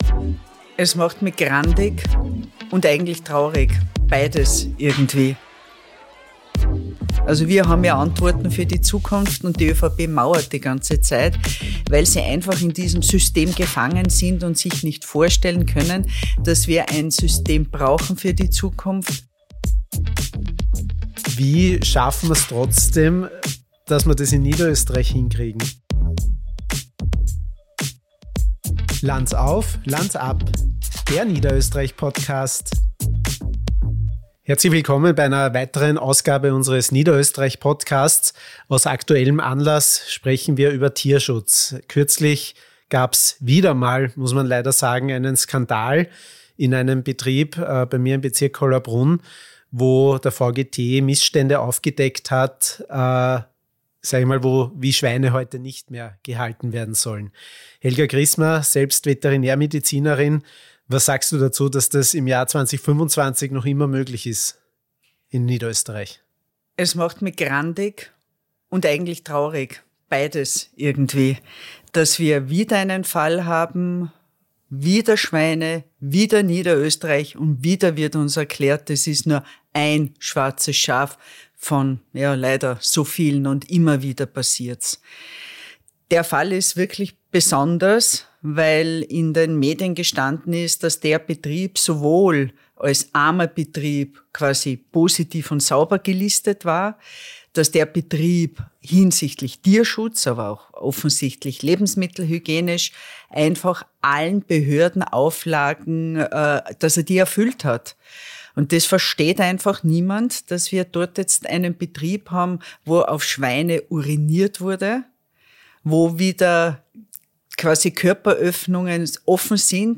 In dieser Folge spricht Helga Krismer, Klubobfrau der Grünen und selbst Veterinärmedizinerin, mit LAbg. Georg Ecker über das Versagen der Kontrollsysteme, die traurige Realität von Vollspaltenböden und die Notwendigkeit eines kompletten Systemwechsels.